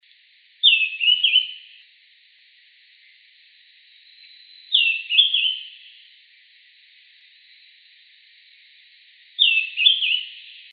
Bailarín Oliváceo (Schiffornis virescens)
Nombre en inglés: Greenish Schiffornis
Fase de la vida: Adulto
Localidad o área protegida: Reserva Privada y Ecolodge Surucuá
Condición: Silvestre
Certeza: Vocalización Grabada
BAILARIN-OLIVACEO.MP3